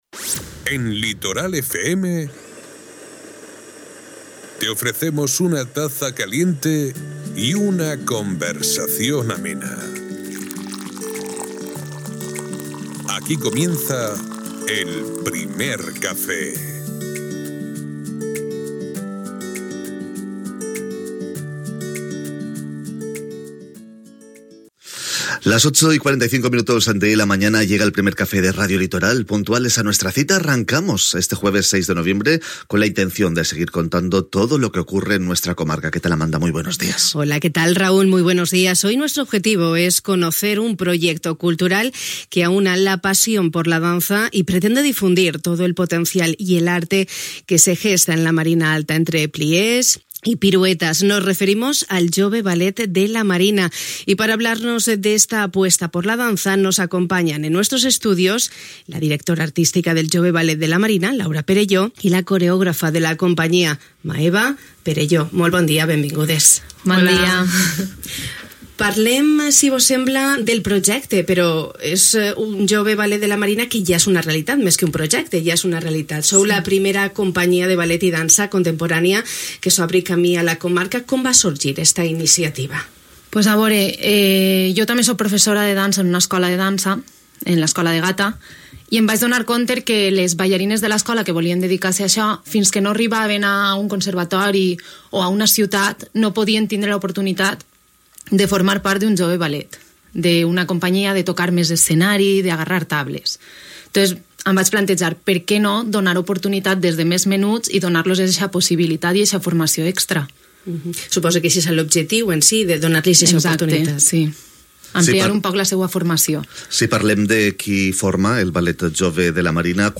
Per això hui, 7 de novembre, al Primer Café de Radio Litoral, hem conegut un projecte que agrupa joves promeses de la dansa a la comarca: el Jove Ballet de la Marina.